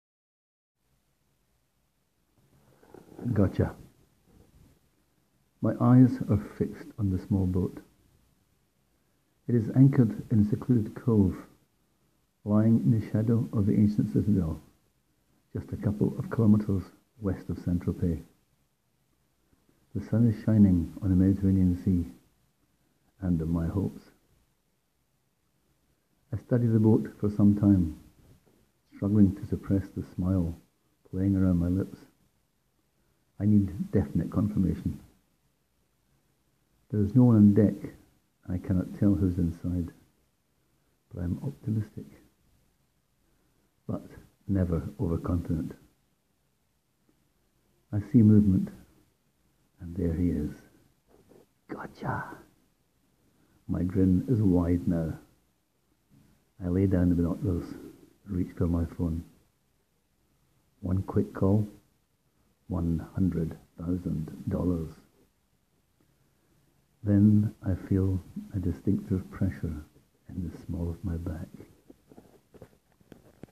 Click here to hear the story read aloud by the author: